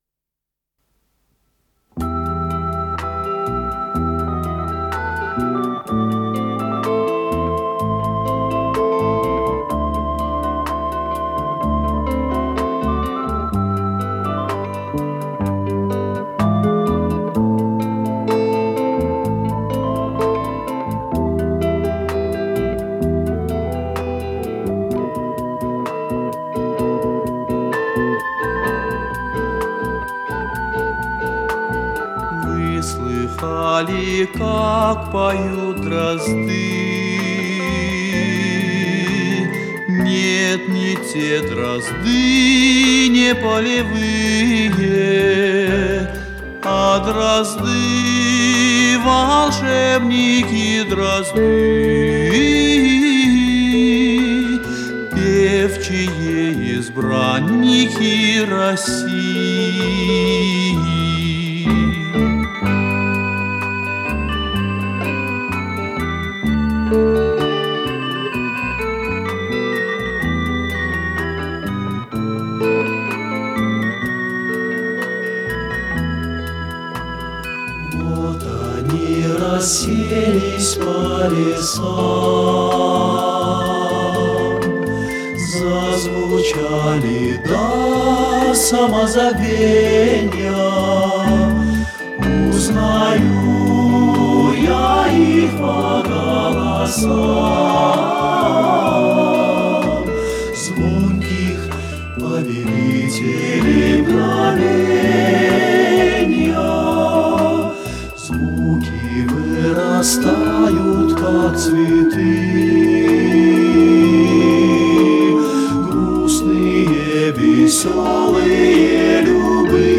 с профессиональной магнитной ленты
Скорость ленты38 см/с
ВариантДубль моно